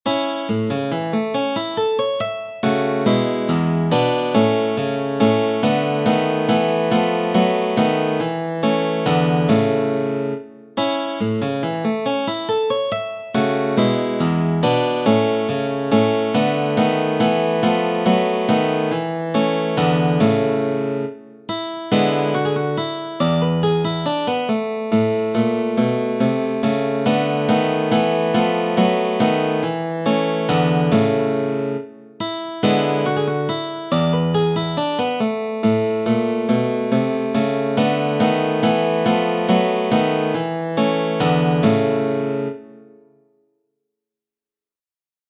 I suoi brani sono freschi e particolarissimi.
36 Landler – n° 14 – in La[►][-♫-]